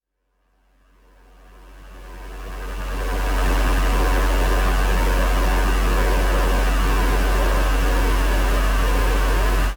HDD.DRONE04.wav